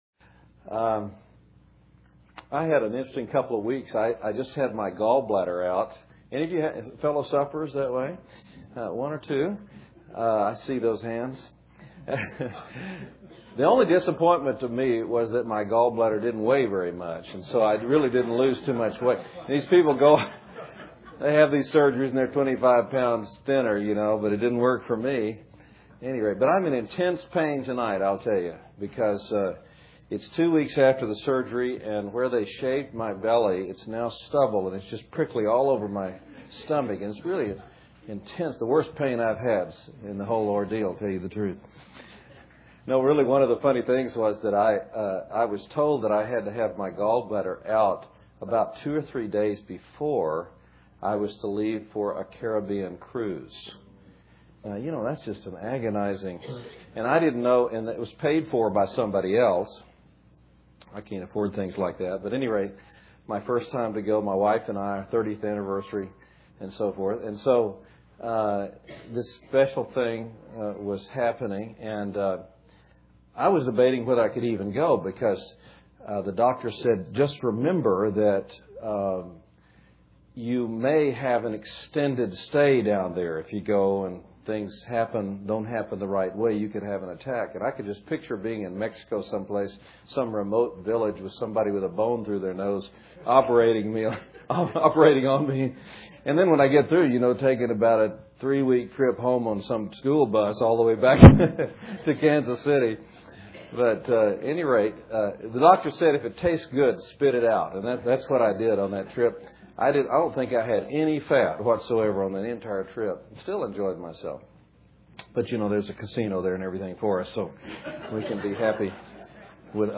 2006 Bible Conference | John 10:1-28